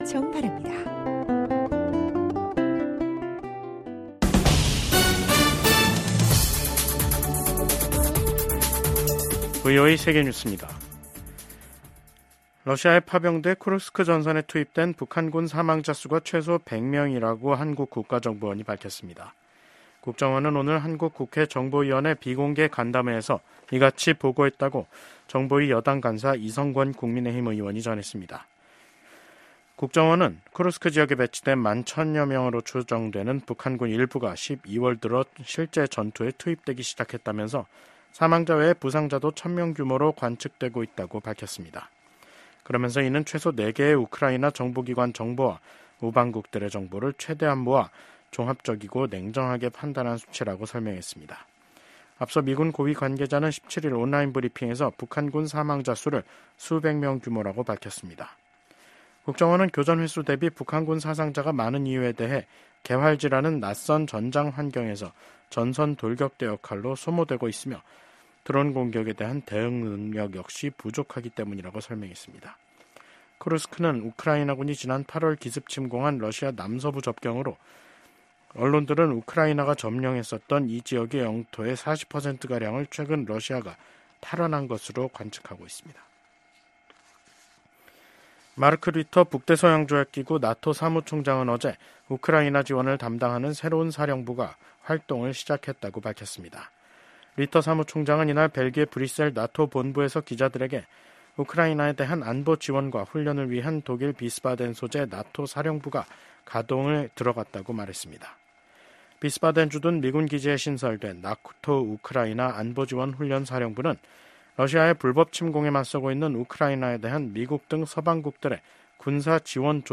VOA 한국어 간판 뉴스 프로그램 '뉴스 투데이', 2024년 12월 19일 2부 방송입니다. 한국 국가정보원은 우크라이나 전쟁에 파견된 북한 군 병사들의 전사 사실을 확인했습니다. 미국과 한국 등 유엔 안보리 이사국들이 북한의 무기 개발과 러시아에 대한 병력 파병과 무기 제공을 강하게 규탄했습니다. 미국 상원의원들은 중국이 한국의 정치적 불안정을 이용해 윤석열 대통령의 대중 정책을 바꾸고 역내 질서를 재편하려 할 가능성에 대한 우려를 표했습니다.